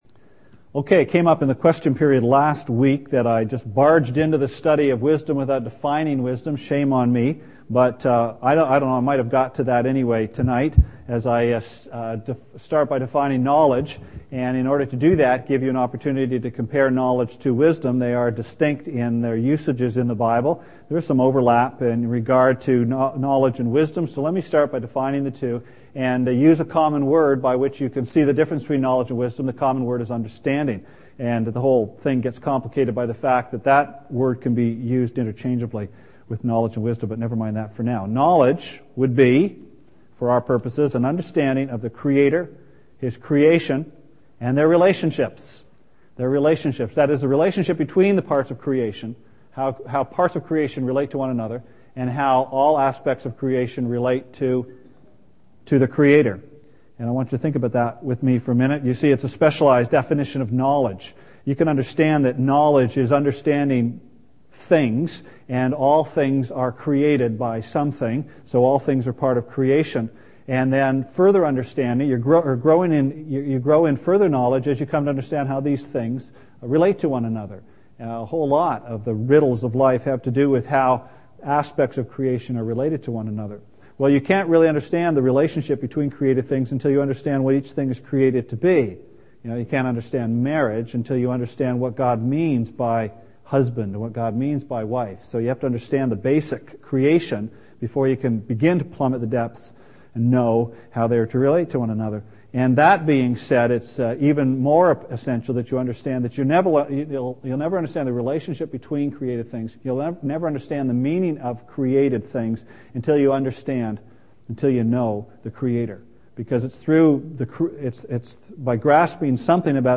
Sermon Archives - West London Alliance Church
Part 2 of a Sunday evening series on Proverbs.